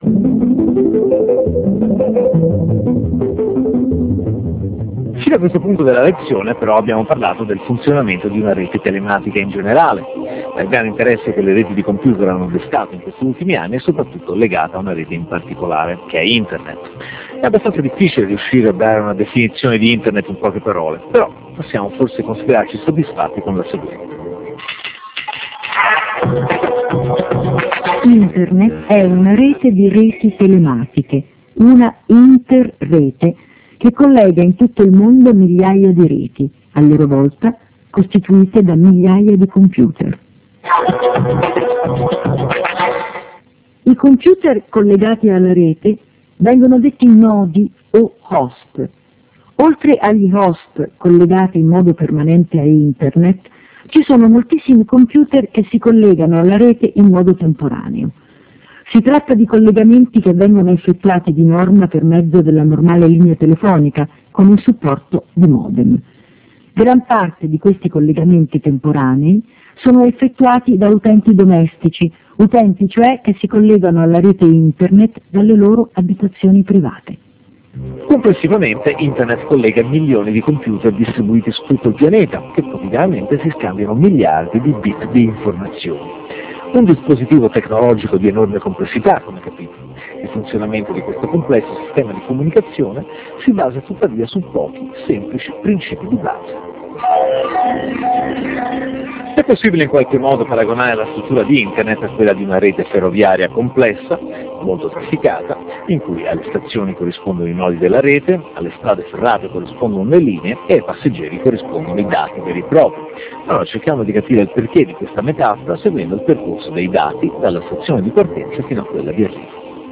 Lezione n. 03